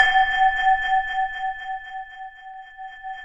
Index of /90_sSampleCDs/Optical Media International - Sonic Images Library/SI2_SI FX Vol 3/SI2_Gated FX 3